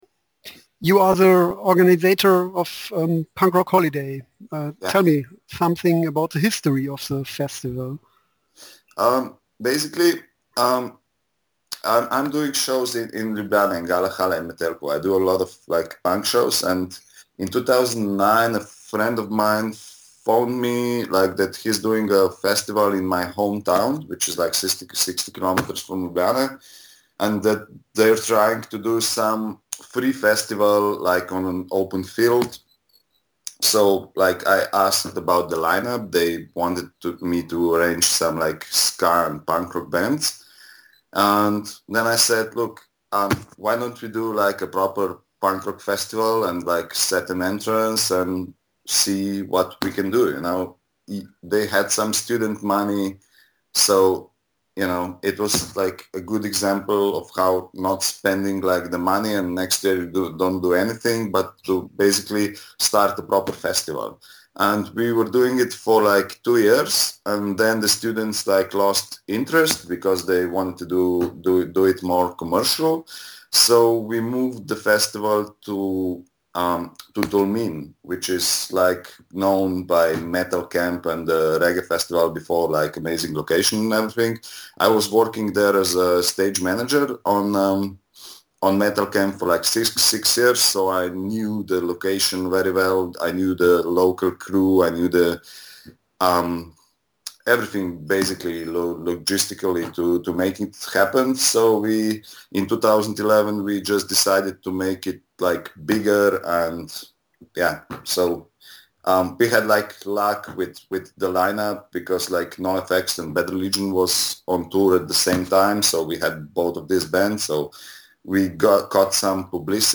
Den haben wir via Skype interviewt.